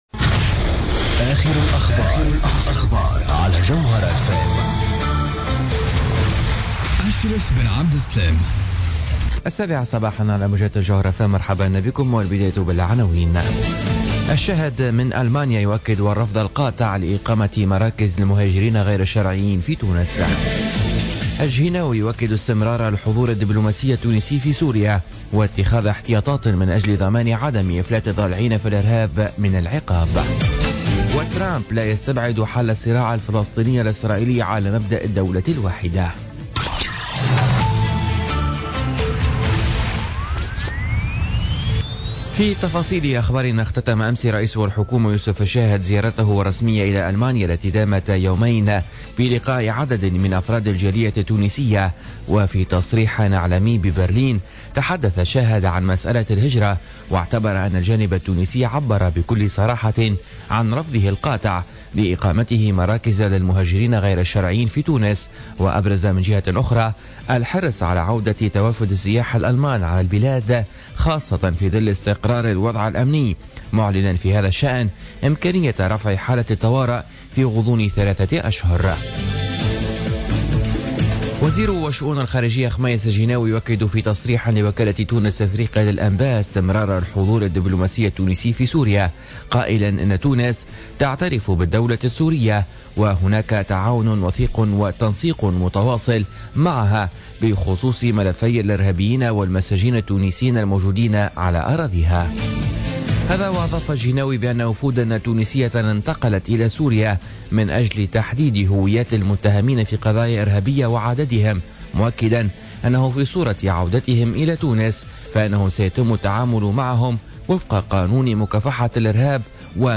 نشرة أخبار السابعة صباحا ليوم الخميس 16 فيفري 2017